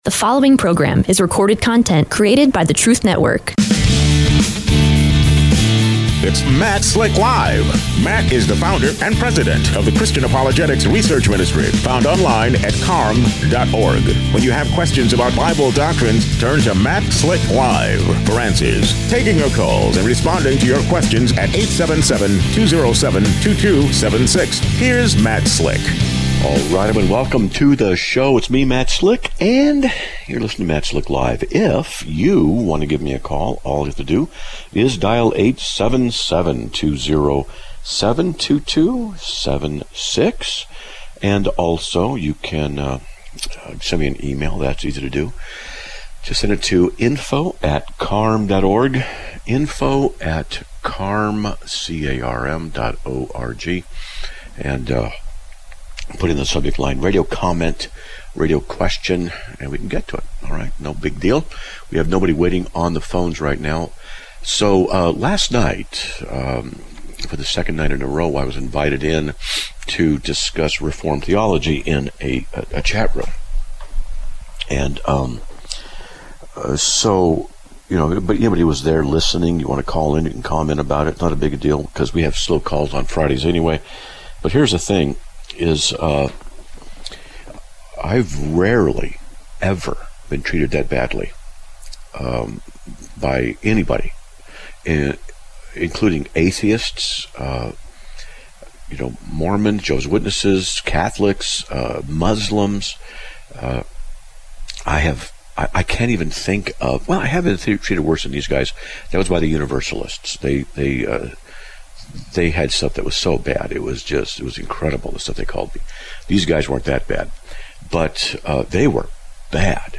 Live Broadcast